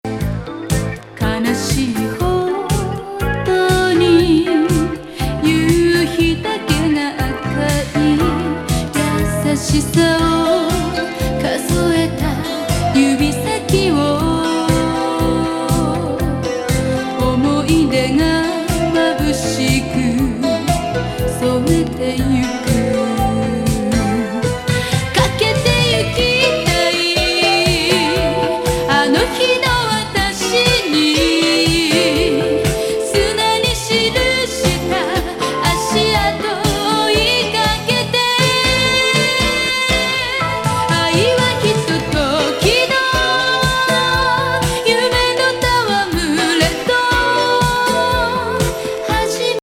極上メロウAOR